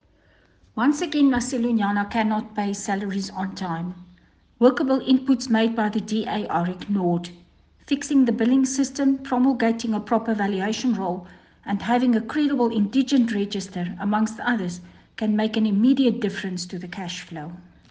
Afrikaans soundbites by Cllr Marieta Visser and Sesotho by Karabo Khakhau MP.